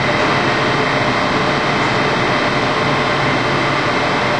quiet_cellblock_cell_amb.ogg